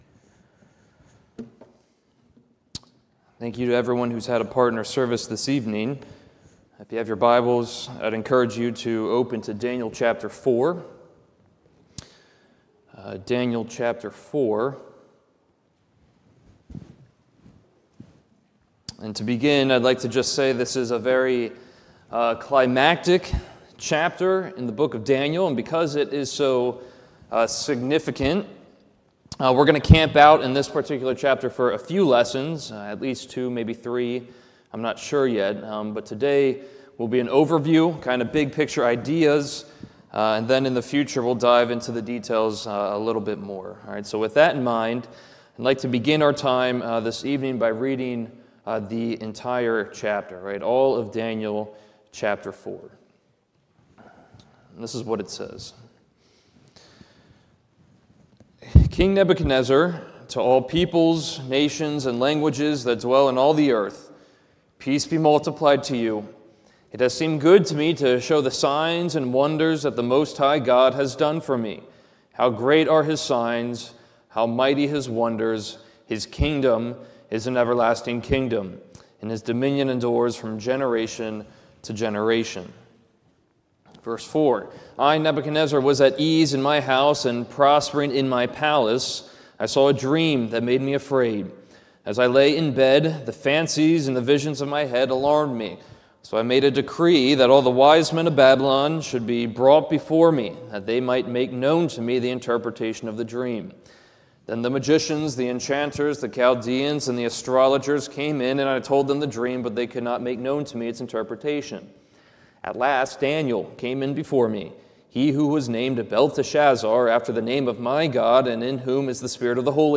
This is a sermon recorded at the Lebanon Bible Fellowship Church in Lebanon